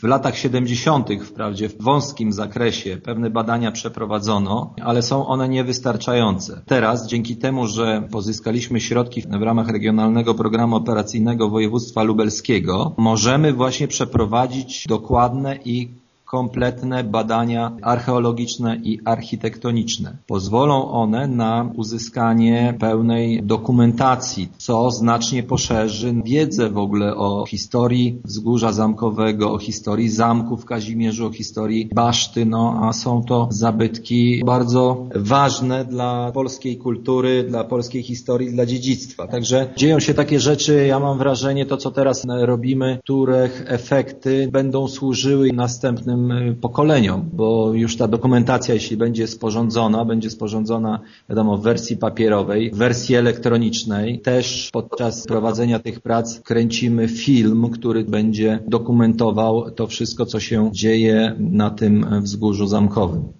Burmistrz Kazimierza Grzegorz Dunia przypomina, że średniowieczny zespół zabytkowy jeszcze nigdy nie był tak gruntownie przebadany pod względem archeologicznym i architektonicznym.